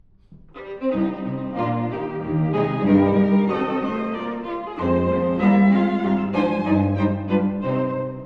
↑古い録音のため聴きづらいかもしれません！（以下同様）
踊りのような雰囲気ですが・・・同時に、とてもシリアスです。
この楽章もｓｆがたくさん出てきます。
あとは、この楽章は転調がとても多いです。